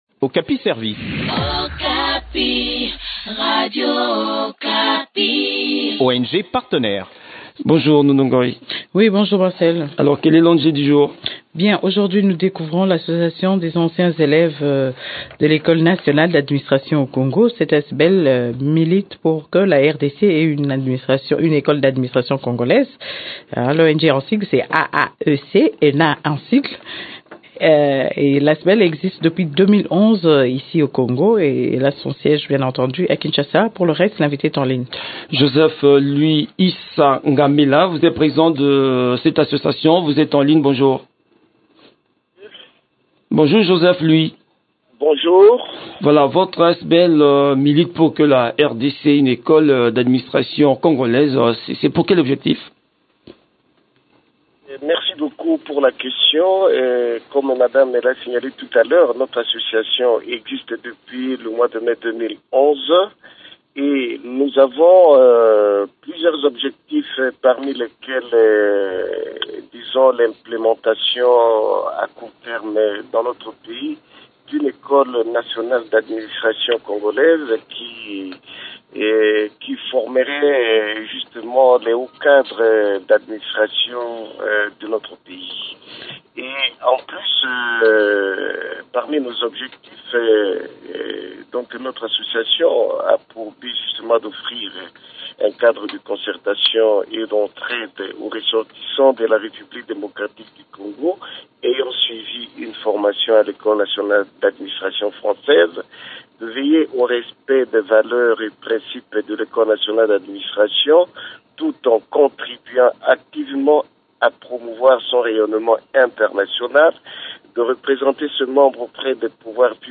Le point des activités de cette structure dans cet entretien